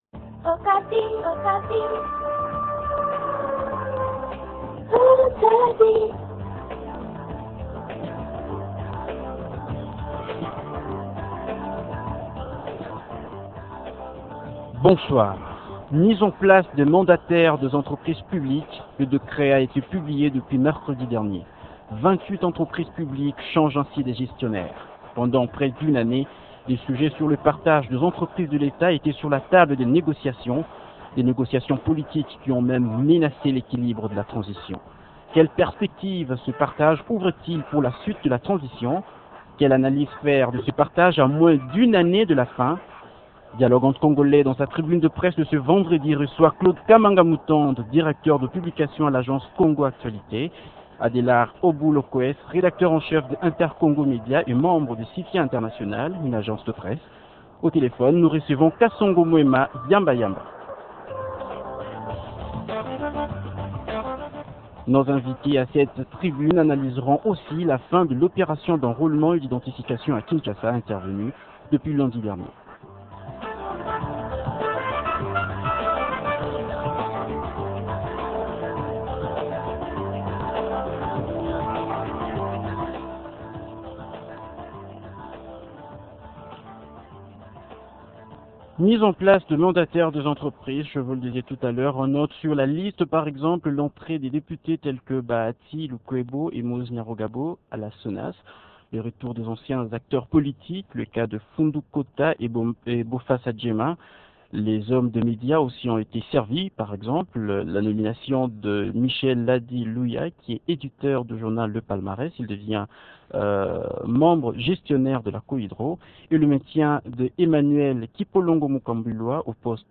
Deux thèmes à débattre ce soir :